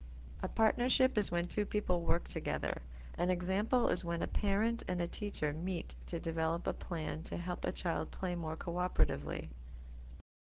When you see the speaker speaker after each of the definitions you can hear the pronunciation of the term and what it means.